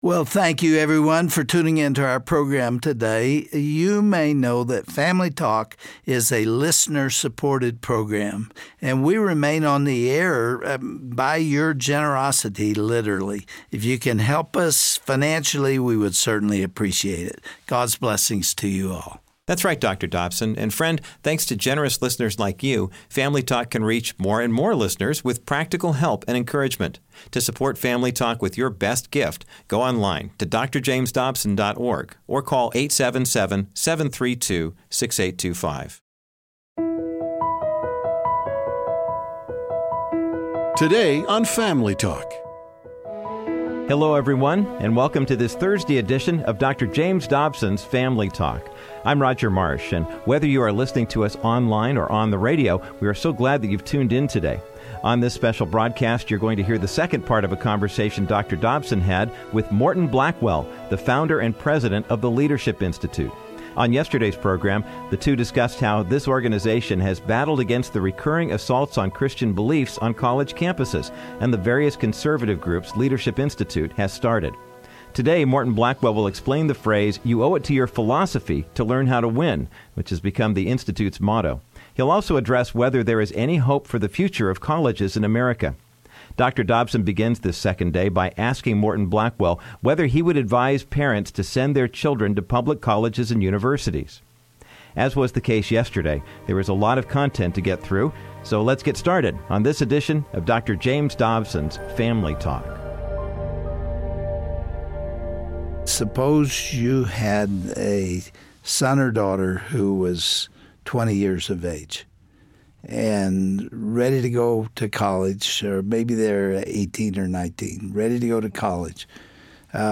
Dr. Dobson concludes his conversation with Morton Blackwell, founder and president of The Leadership Institute. They will discuss how his organization is preparing students to not just fit in with the crowd, but to educate them to pursue meaningful careers.
Host Dr. James Dobson